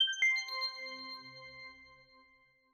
Button_3_Pack2.wav